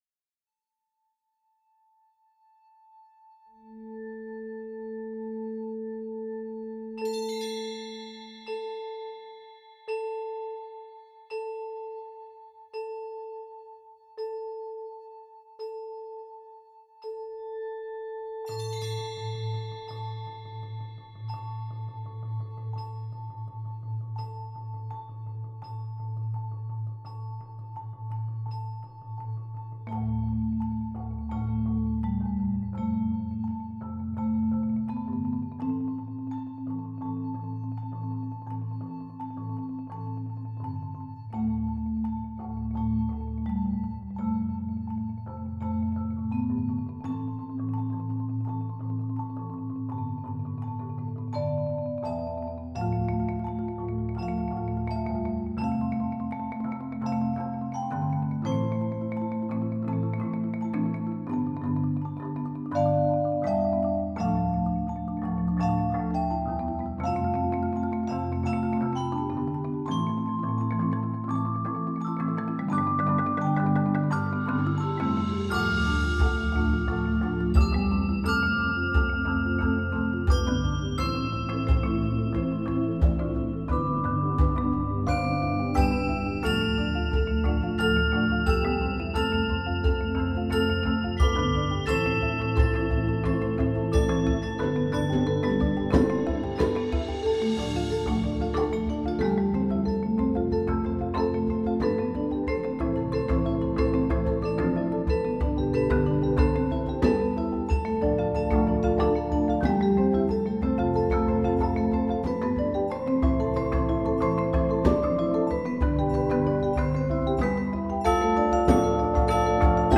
Voicing: 12 Percussion